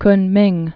(knmĭng)